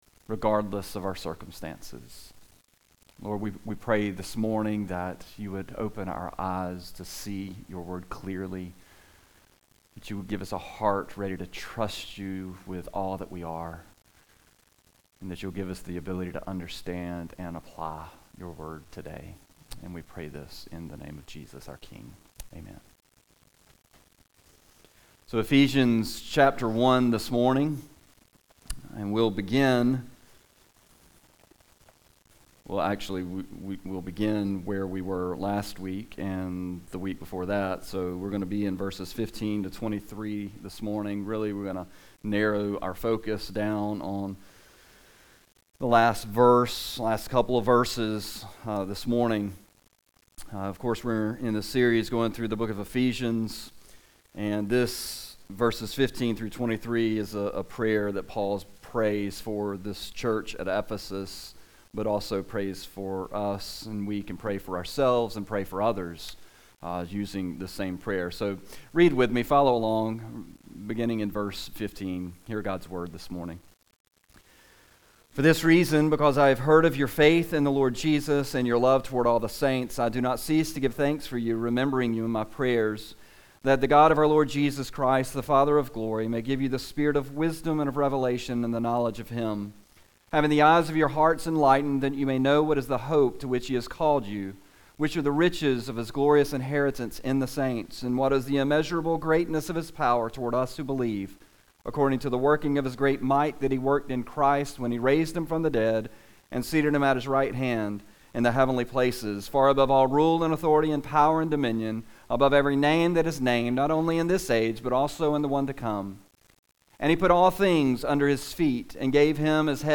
A message from the series "Church Membership."